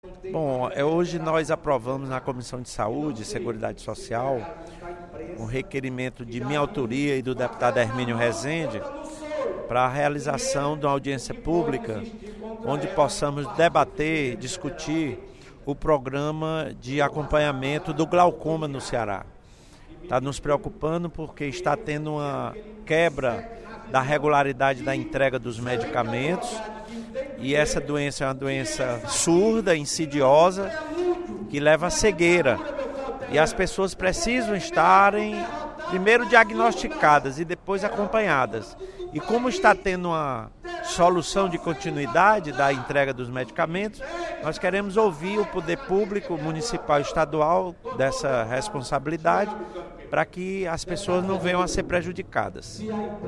Durante o primeiro expediente da sessão plenária desta terça-feira (10/06), o deputado Lula Morais (PCdoB) destacou a aprovação, hoje, na Comissão de Seguridade Social e Saúde da Assembleia Legislativa, de requerimento de sua autoria solicitando a realização de audiência pública para discutir o Programa Municipal de Tratamento de Glaucoma em Fortaleza.